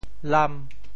安南 潮语发音 展开其他区域 潮州 ang1 lam5 潮州 0 1 中文解释 越南 越南社会主义共和国（越南语：Cộng hòa Xã hội Chủ nghĩa Việt Nam，英语：Socialist Republic of Vietnam），简称“越南”，是亚洲的一个社会主义国家。